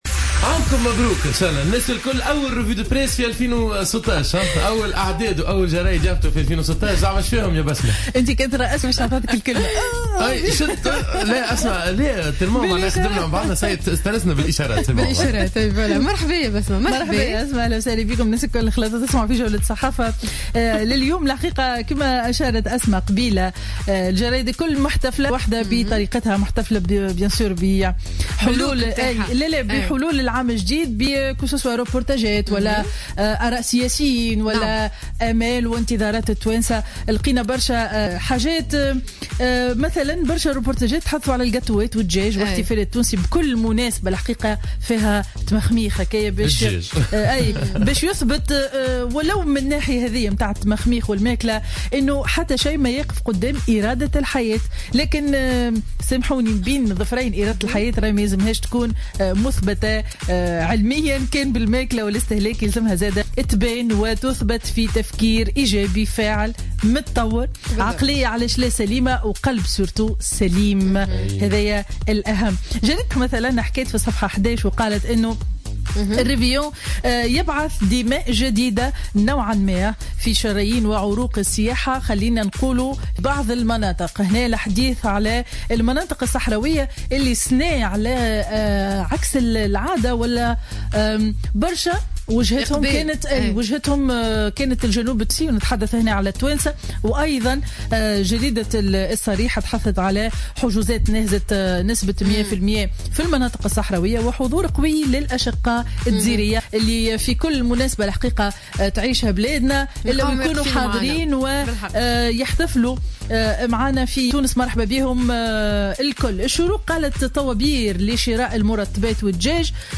Revue de presse du vendredi 1er janvier 2016